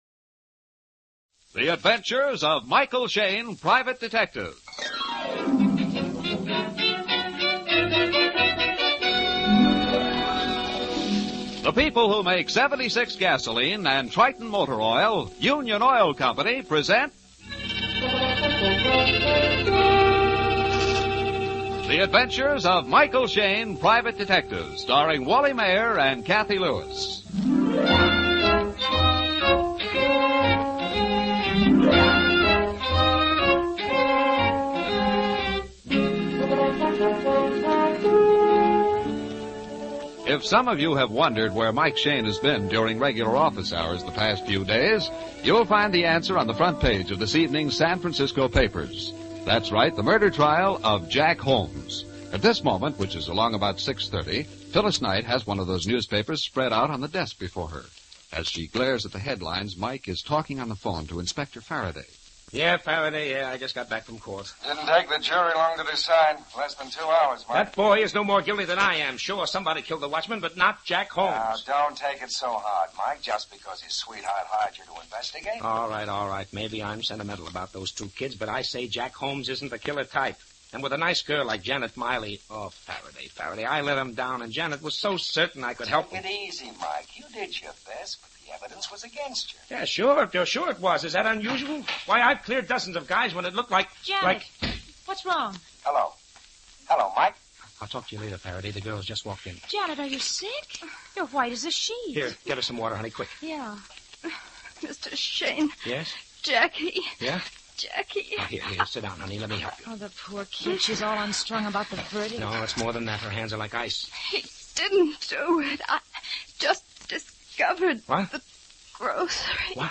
Michael Shayne 450521 Murder Trial Of Jack Holmes, Old Time Radio